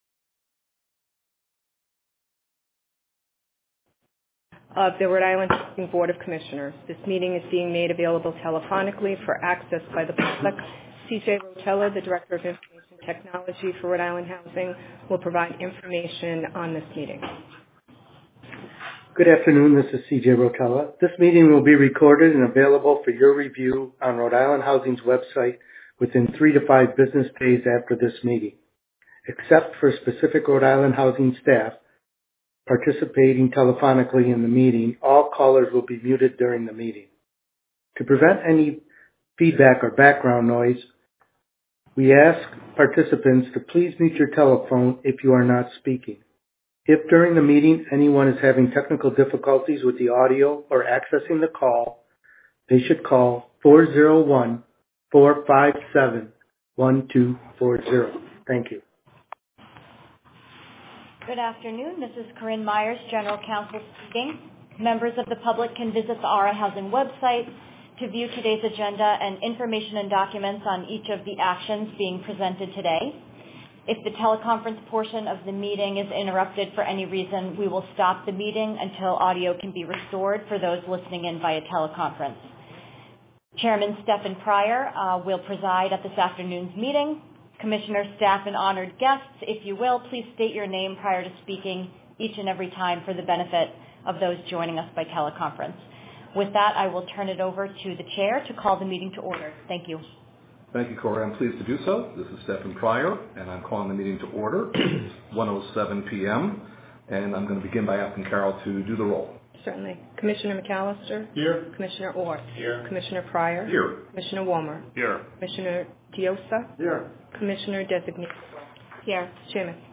Recording of RIHousing Board of Commissioners Meeting: 05.16.2024